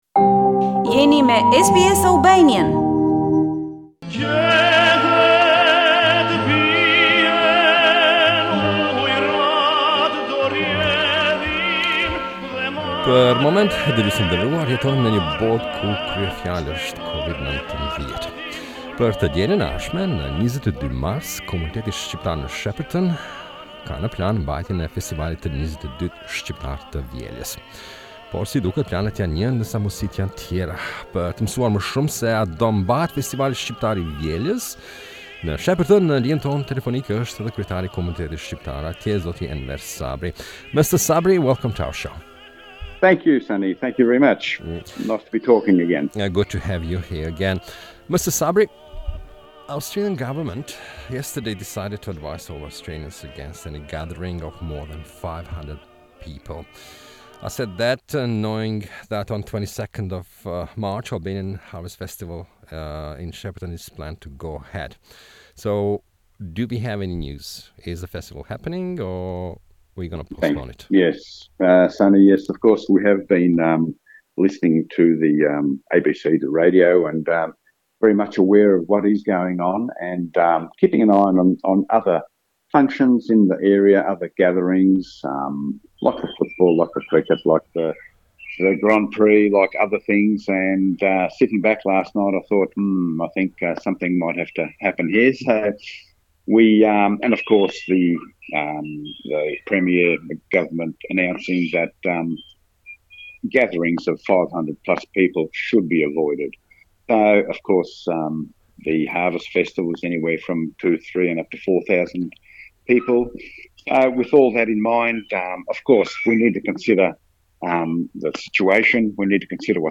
With the restrictions for COVID- 19 in place, one of the most successful Albanian activities in Victoria is in doubt. We interviewed one of the organisers of the Shepparton Albanian Harvest Festival